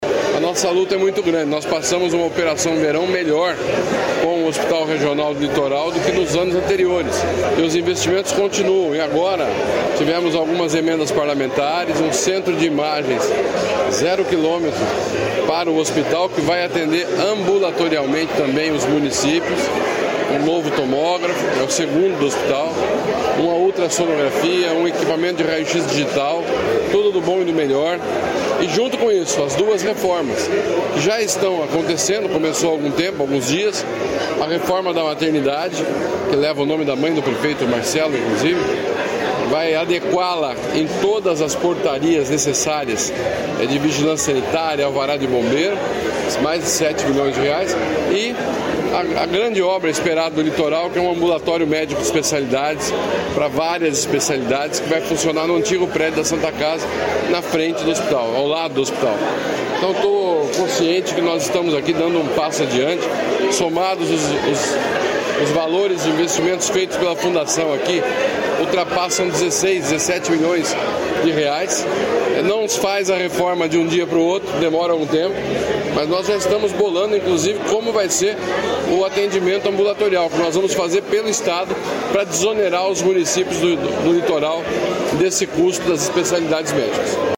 Sonora do secretário da Saúde, Beto Preto, sobre a destinação de R$ 15 milhões para reforçar o atendimento à saúde em Paranaguá e região